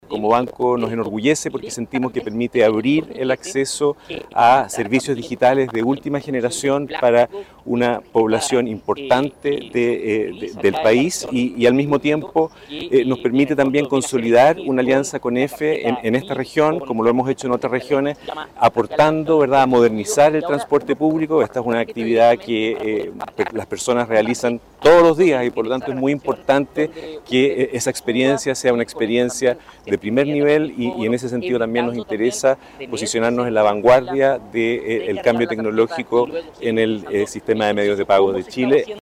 El presidente de esta institución, Daniel Hojman, mencionó que como banco valoran su participación en este acuerdo.